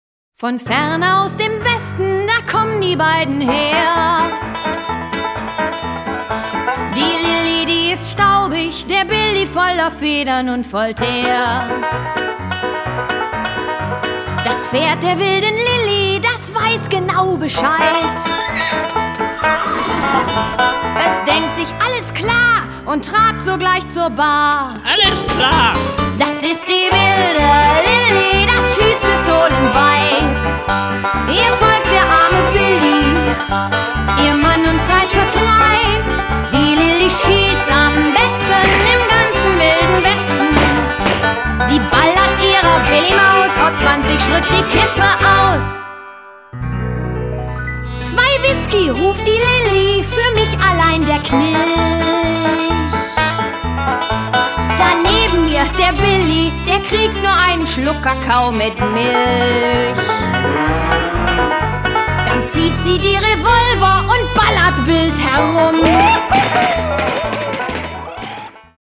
Eine CD mit allen Liedern und der Geschichte zum Anhören
Kinder-Musicals selbst aufführen?